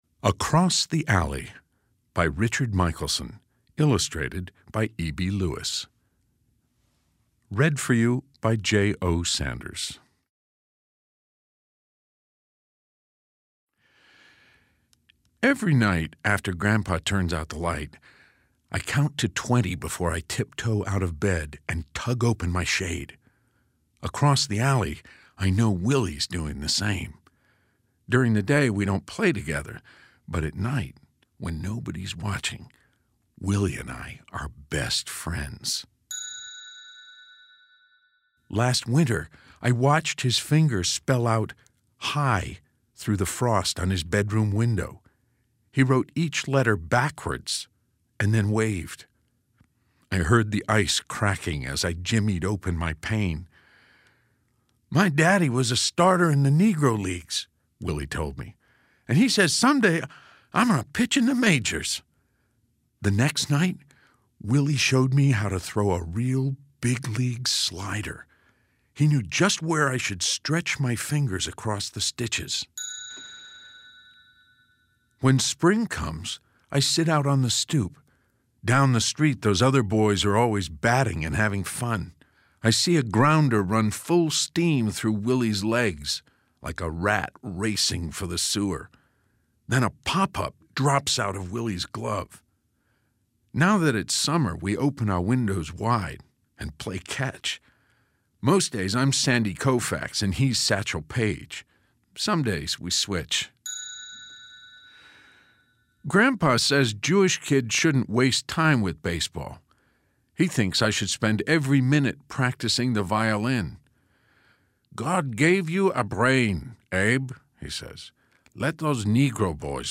Listen to Jay O. Sanders reading of Across the Alley: